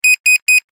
Three Beep Alert Sound Effect
Description: Three beep alert sound effect. Short, sharp beep tones create a clear, attention-grabbing pattern.
Genres: Sound Effects
Three-beep-alert-sound-effect.mp3